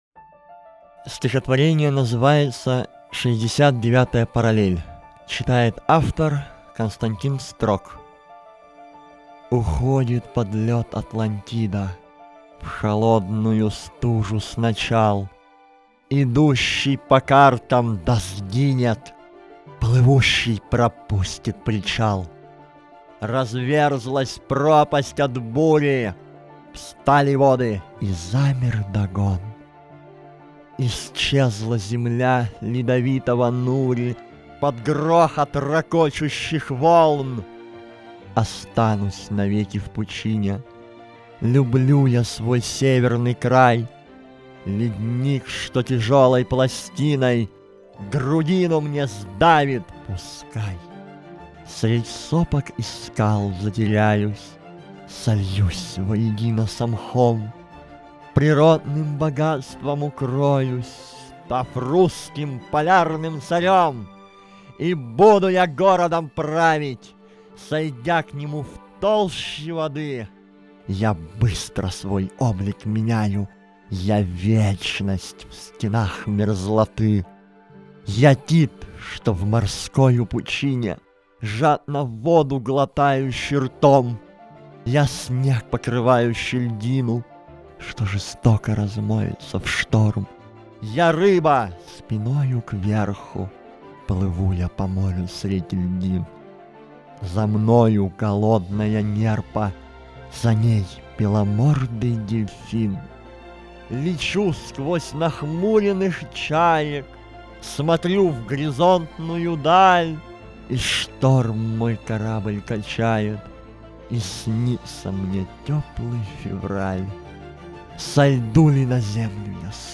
Каждый читатель сможет услышать в нём что-то родное и близкое, проникнуться теплотой слов и приятными голосами исполнителей.
Для вас читают: